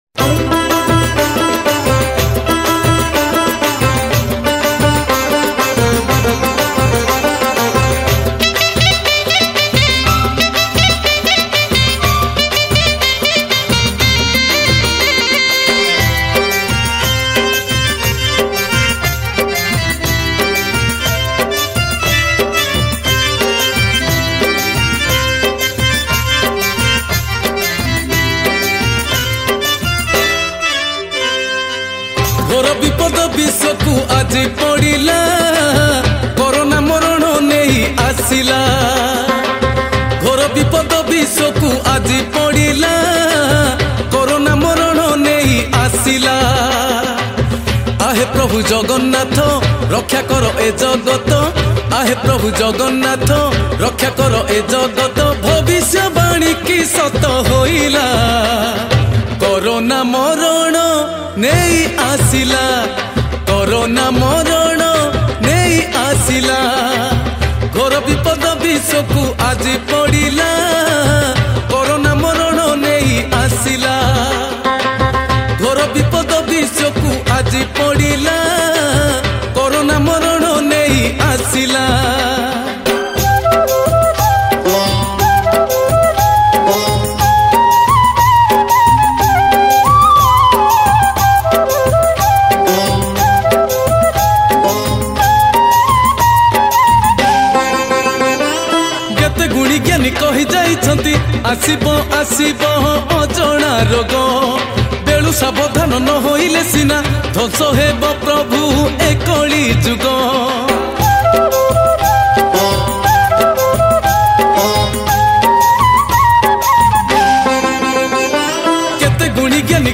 Jagannath Bhajan